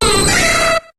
Cri de Debugant dans Pokémon HOME.